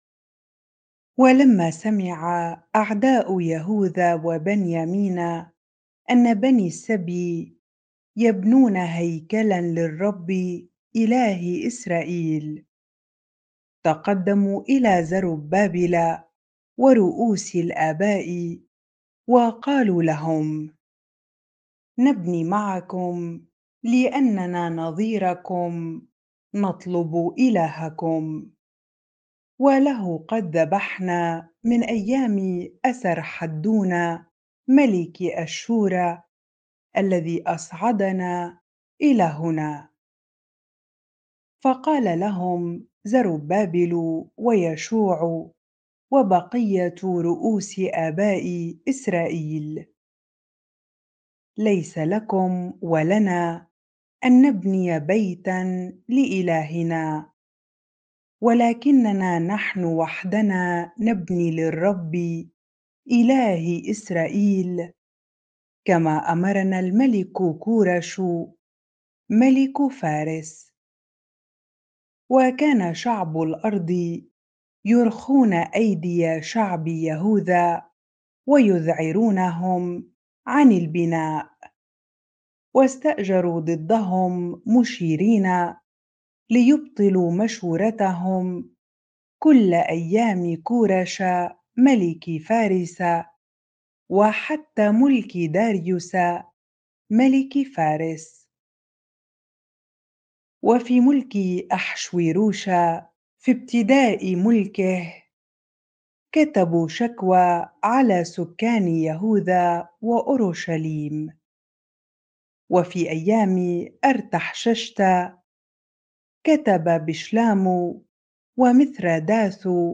bible-reading-Ezra 4 ar